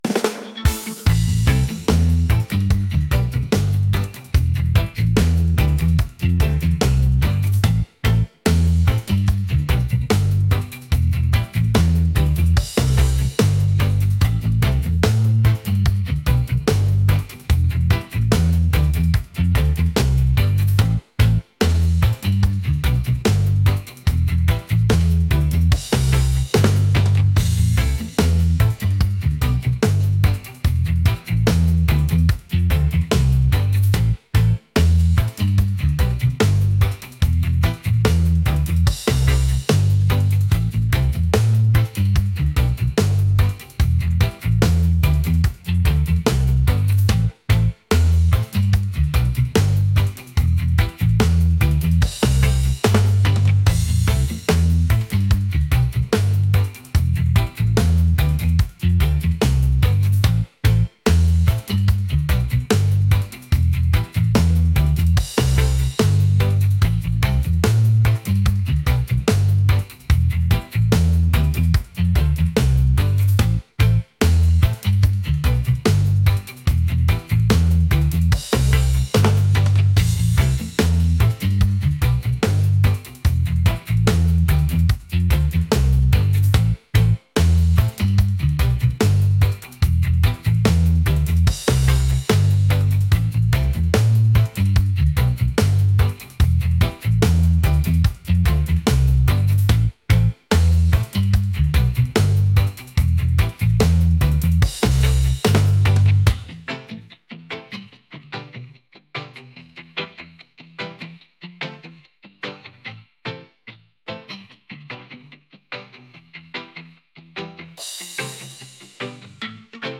reggae | upbeat | energetic